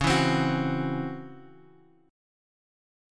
C#mM7bb5 chord {9 9 10 9 x 9} chord